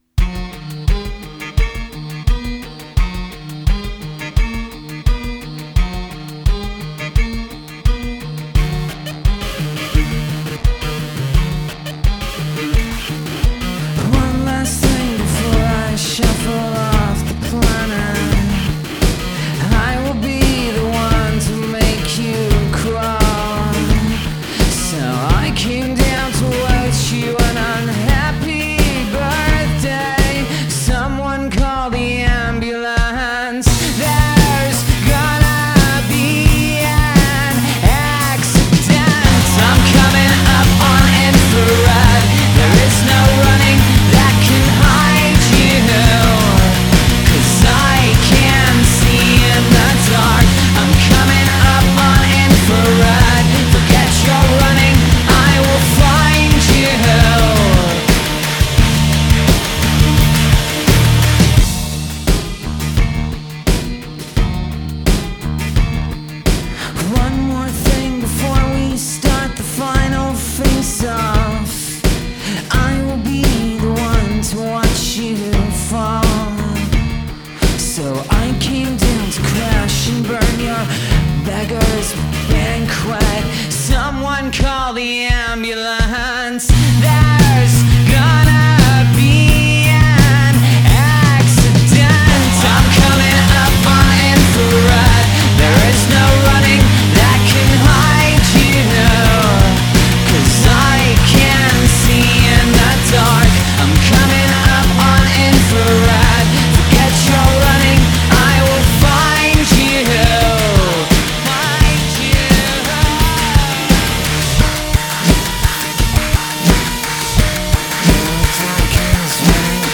Alternative rock Indie rock Rock